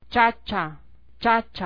Tabla I: Alfabeto Oficial sonorizado
Africada simple .. ch